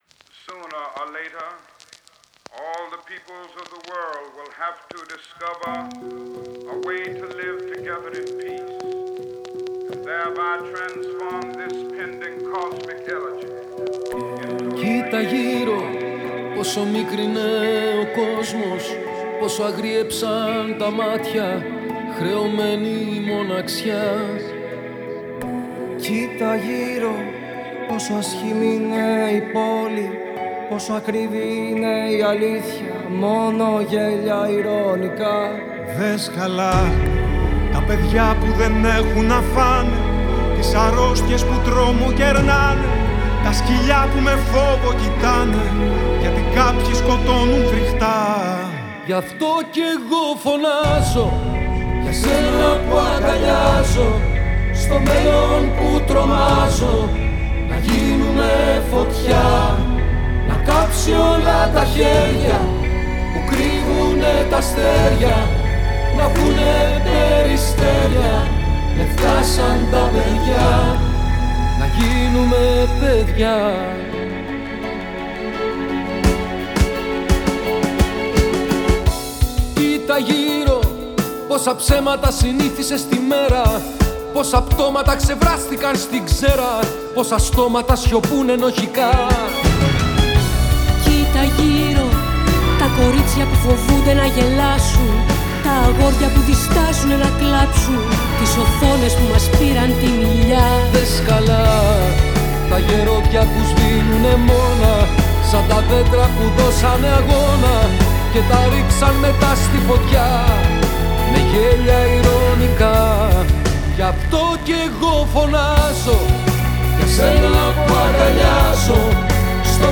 ελληνικό τραγούδι